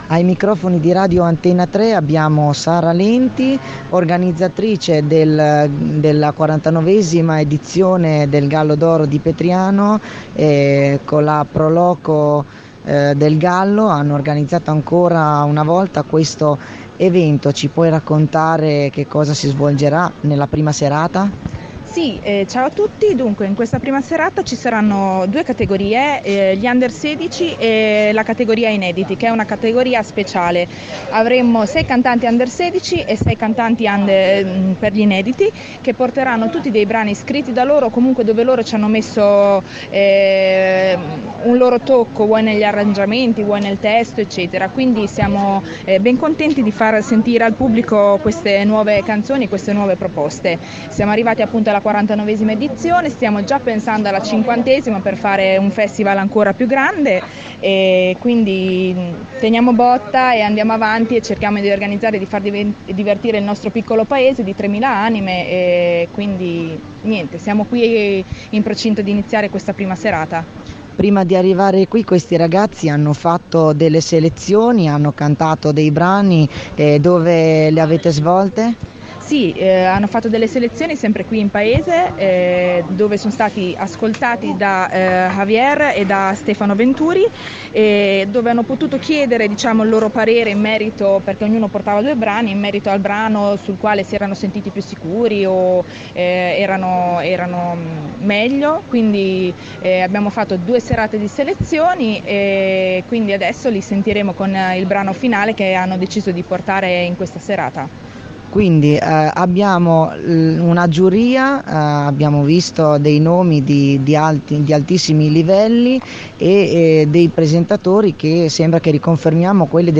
Le Interviste
durante la prima serata del “Gallo D’Oro Festival” a Petriano.